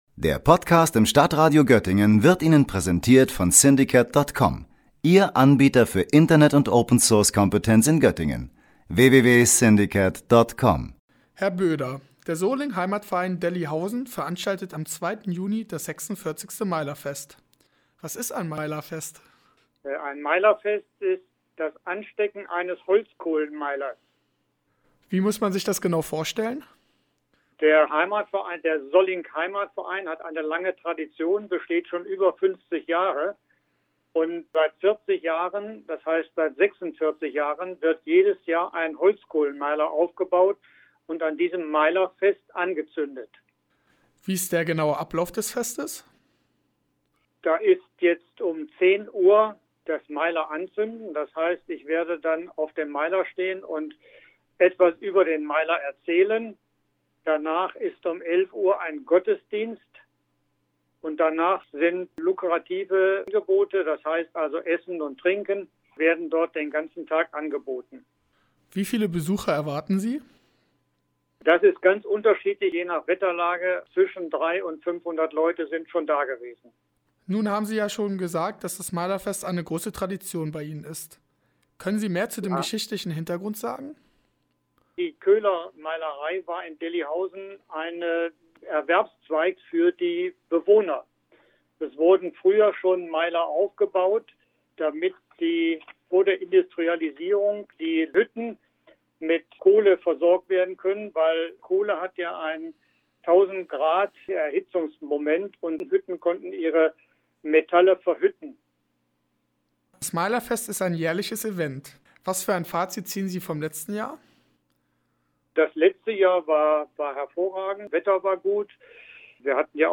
Interview_Meilerfest_on-playout.mp3